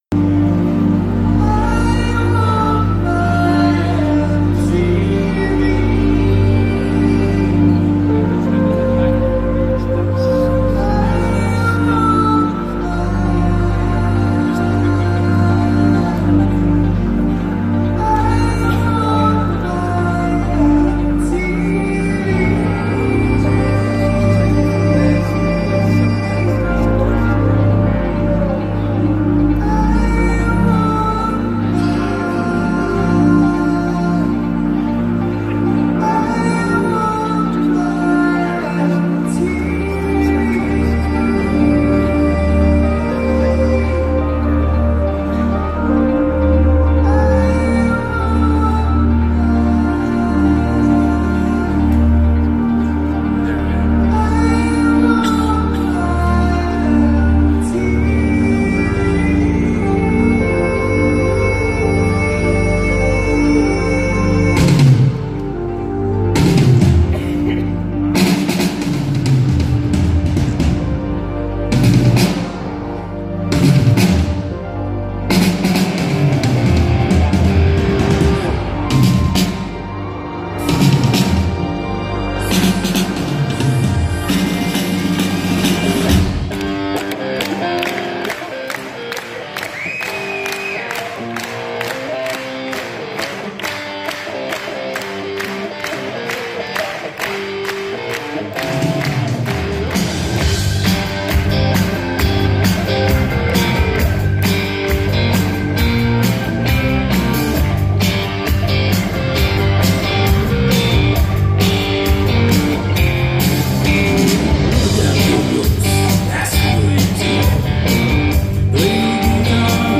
(full band)
You can't play that song without that tone.